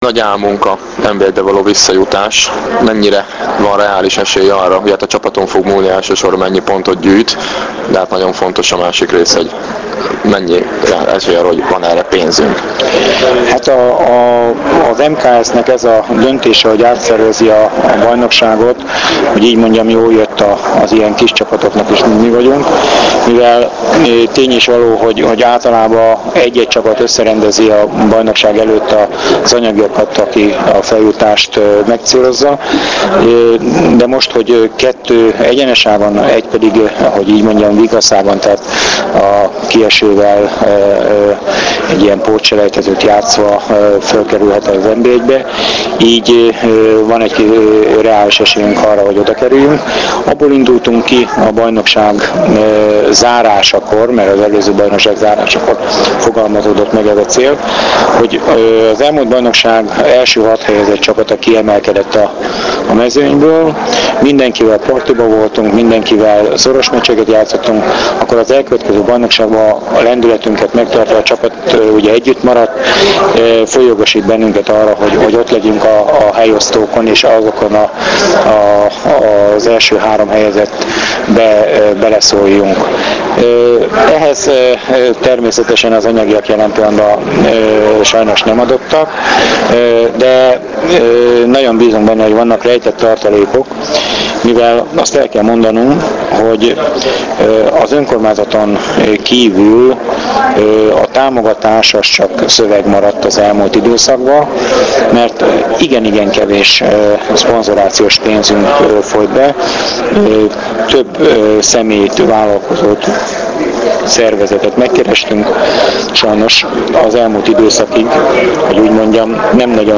Az interjú mp3-ban >>>